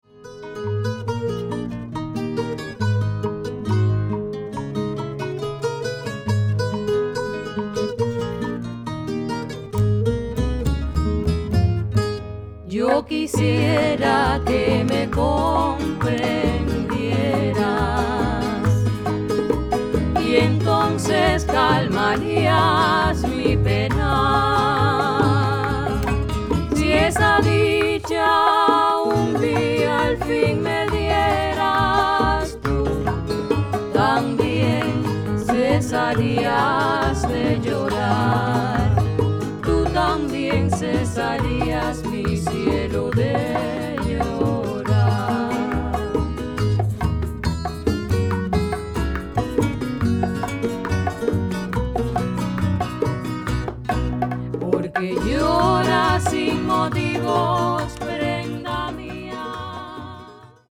Soaring Vocal Harmonies!
• Music sung in Spanish
classical guitar
Recorded at STUDIO EUSEBIO DELFIN - Cienfuegos, Cuba